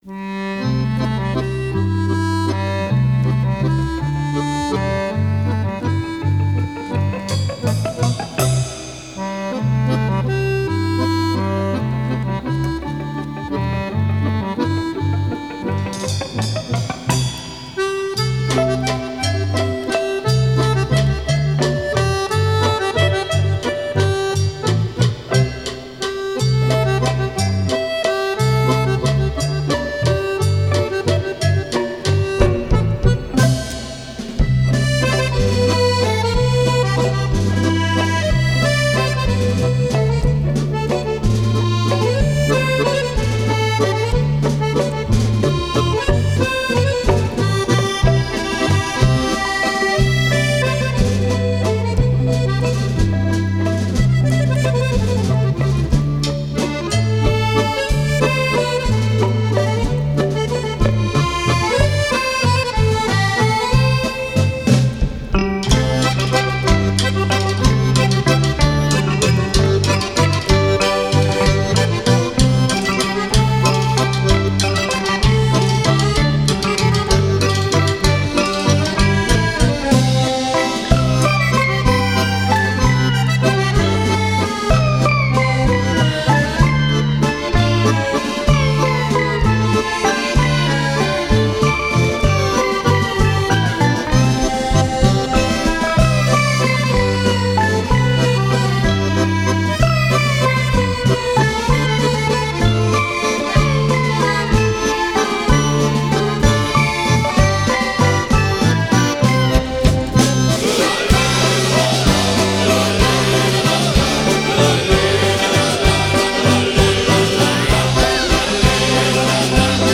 скрипка, вокал.
бас, вокал.
аккордеон, ф-но, вокал.
ударные.